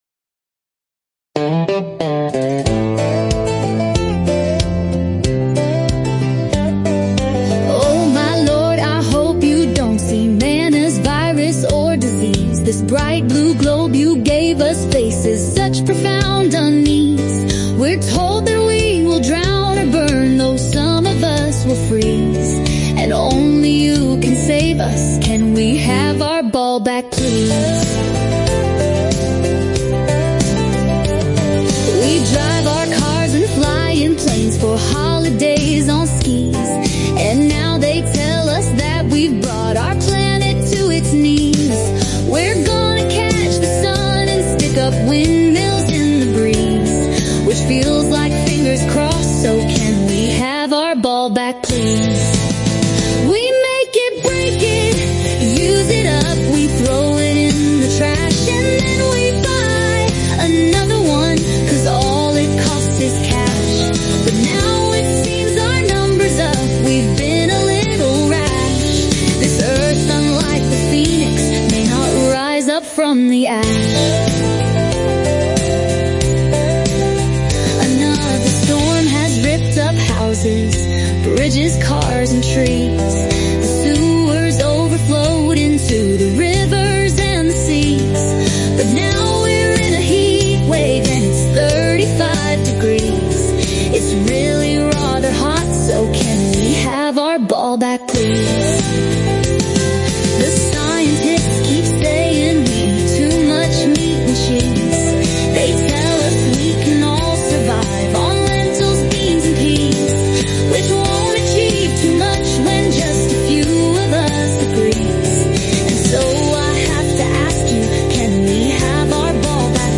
Lyrics by a human, namely, me, but...
The music is not human ;-)